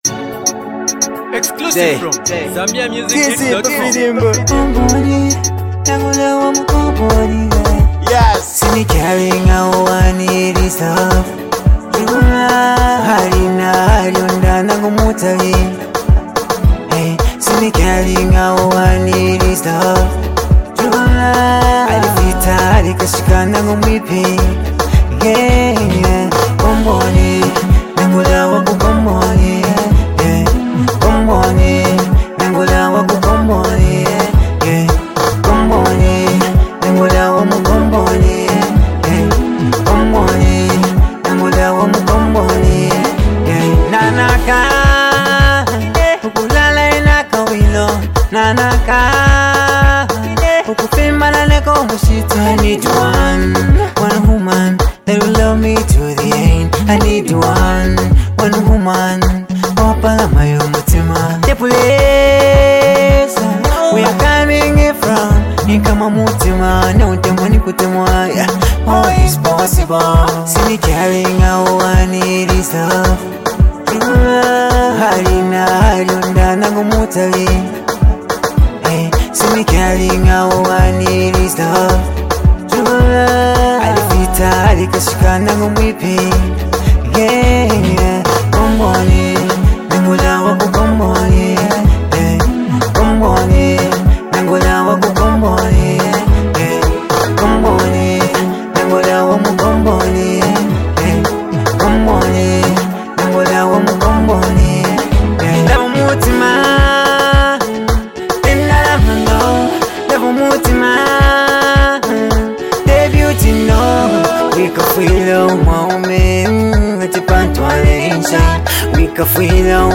Mashup tune
love song